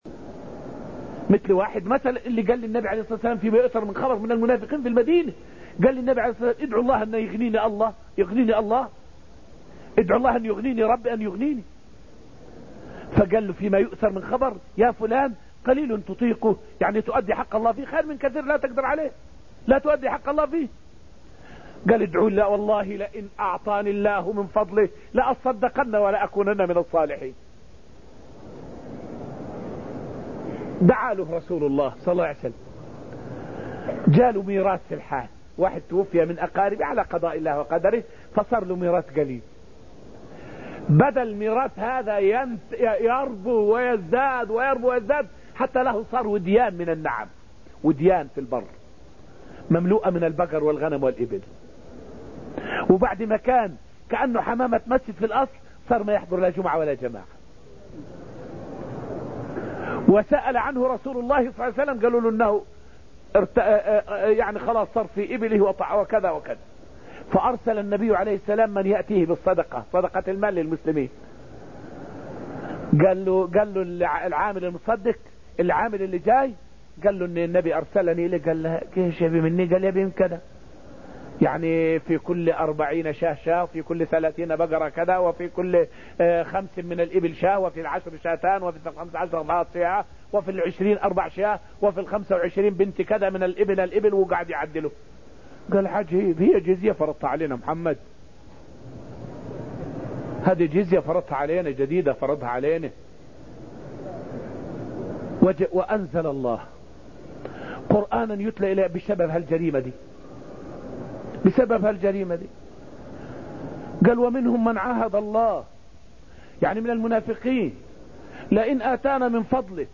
فائدة من الدرس الثامن عشر من دروس تفسير سورة الحديد والتي ألقيت في المسجد النبوي الشريف حول خلف الوعد مع الله من أسباب النفاق.